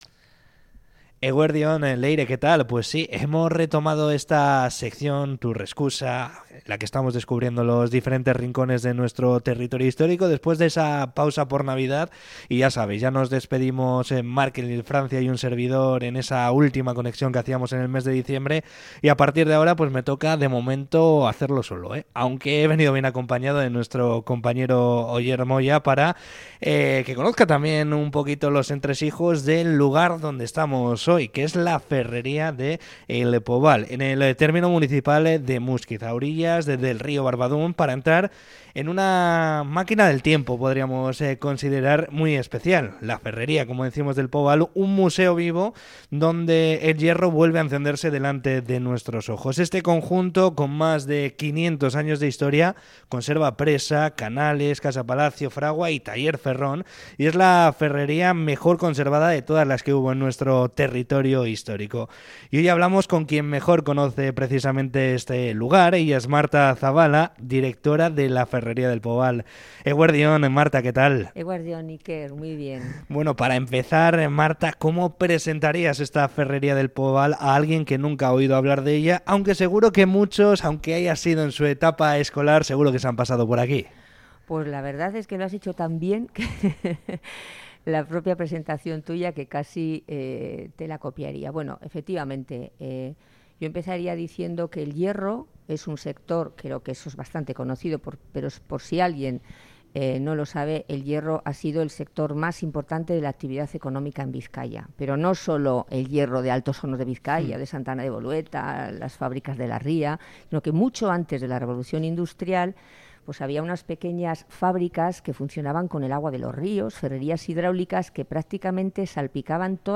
ENTREVISTA-POBAL-from-Quantum-3.mp3